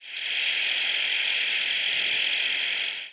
Le Conte's Sparrow Ammodramus leconteii
Flight call description A high, descending "ssews" with a sibilant quality.
Fig.1. Oklahoma October 25, 1995 (WRE).
Perched bird.
The frequency track was double-banded, descending, and slightly upward-arched.